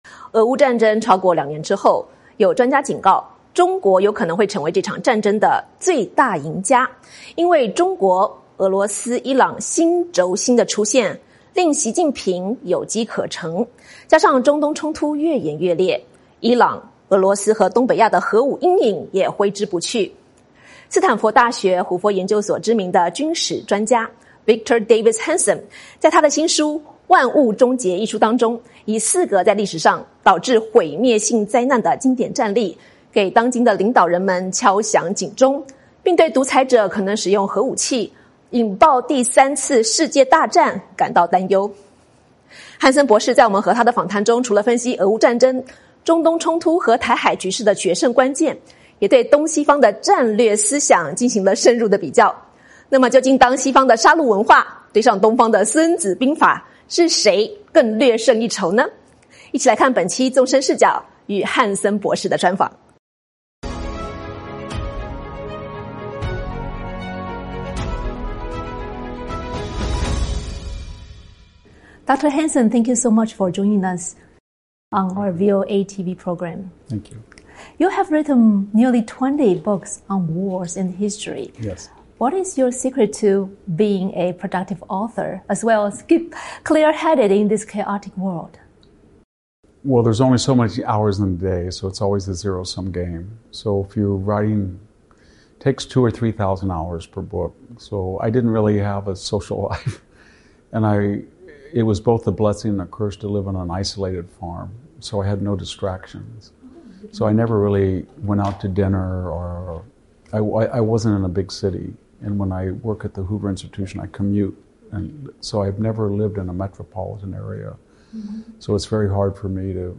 专访军史学家汉森：中国是乌战的最大赢家
美国之音邀请汉森博士作客本期《纵深视角》为您带来深入分析。